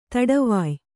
♪ taḍavāy